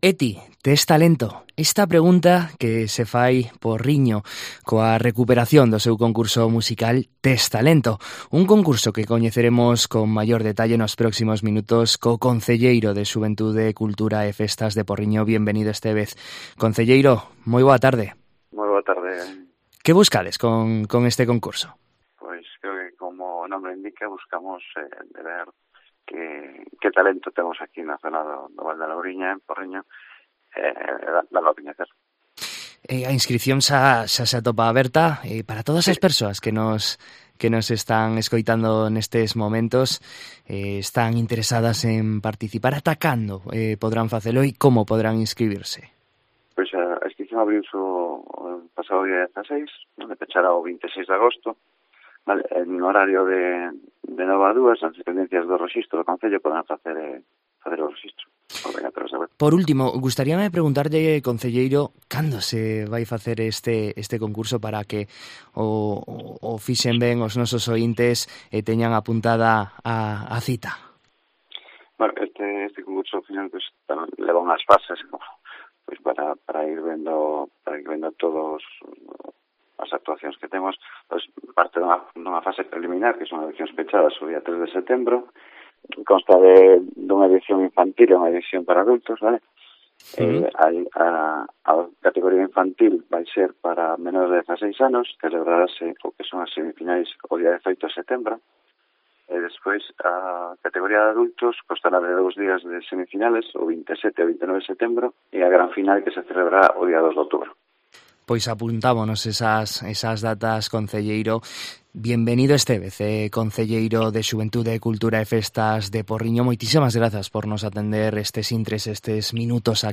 En COPE Vigo hablamos con el concelleiro de Xuventude, Cultura e Festas de Porriño, Bienvenido Estévez, sobre el concurso "Tes talento?"